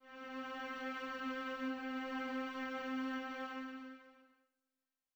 Strings2.wav